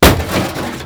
impact_3.wav